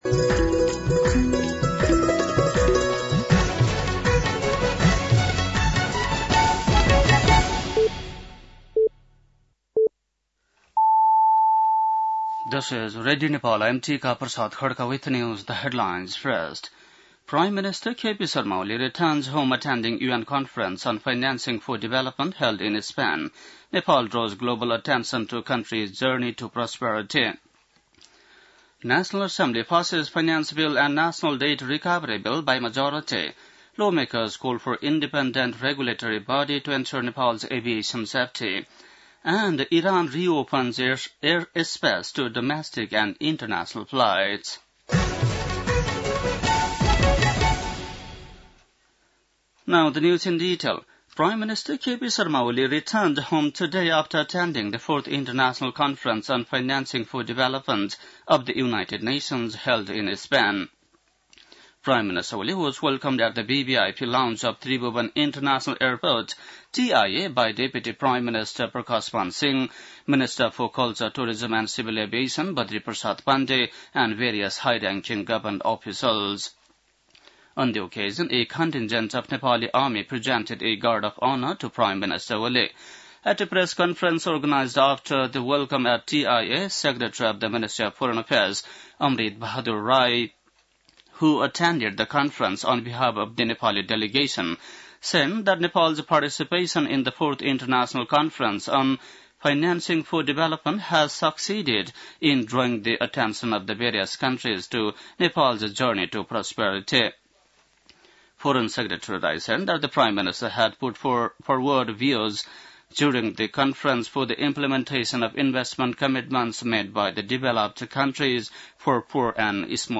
बेलुकी ८ बजेको अङ्ग्रेजी समाचार : २० असार , २०८२